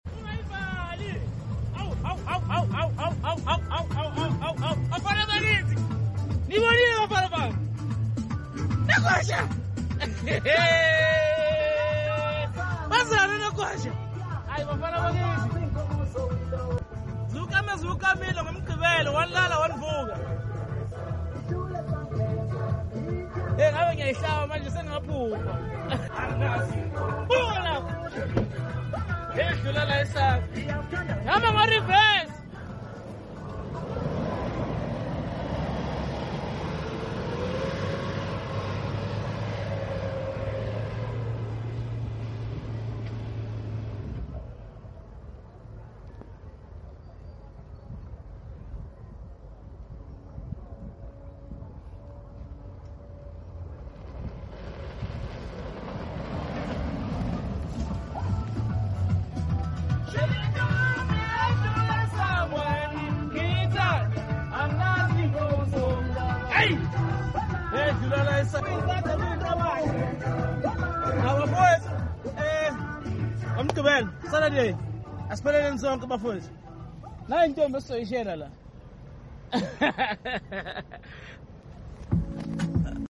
Another 4JJ3 Isuzu in for a custom stage 2 remap!!